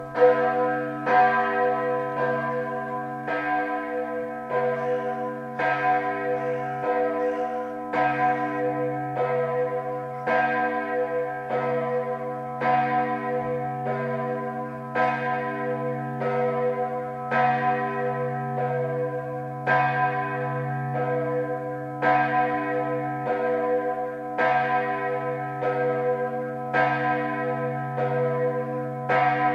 Herz-Jesu-Glocke
• Die Herz-Jesu-Glocke ist mit 2573kg und 163cm Durchmesser die schwerste und größte Glocke.
Herz-Jesu-Glocke.mp3